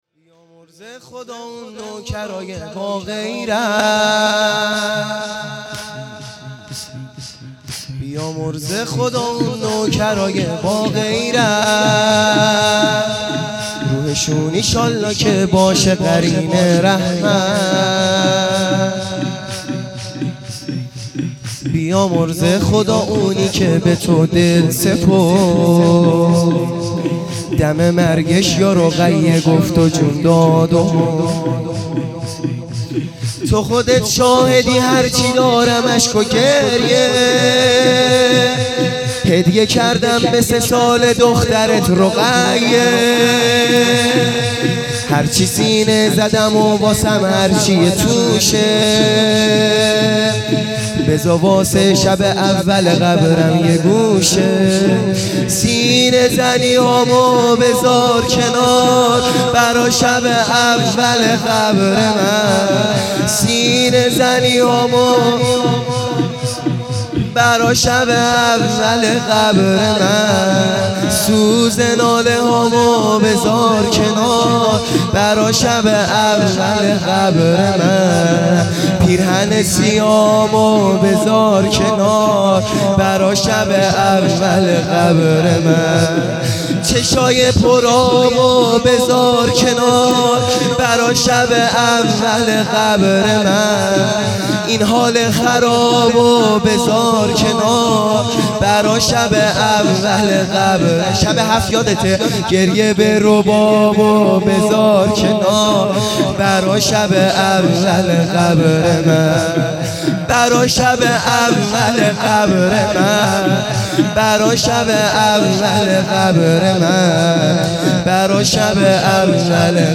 شور | بیامرزه خدا اون نوکرا